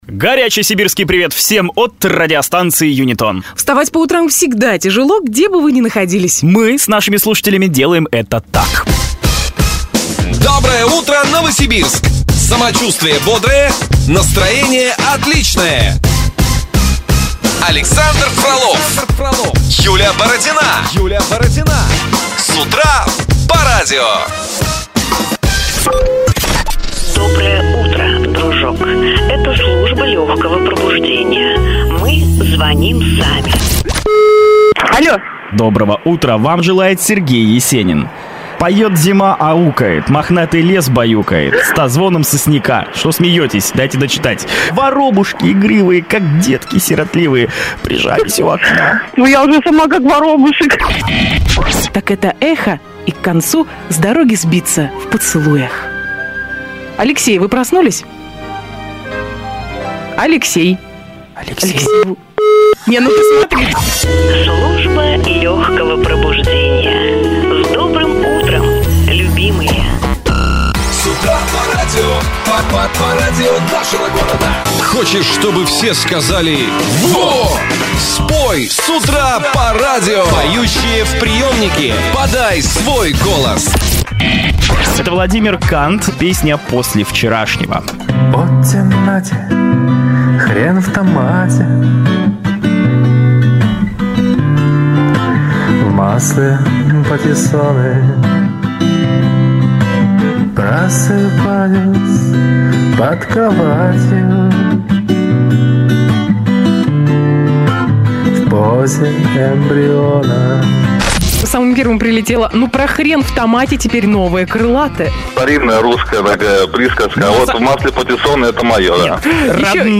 Утреннее шоу радио "Юнитон" (Новосибирск) вышло в финал "Радиомании 2012".